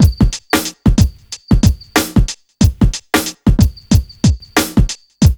1HF92BEAT2-L.wav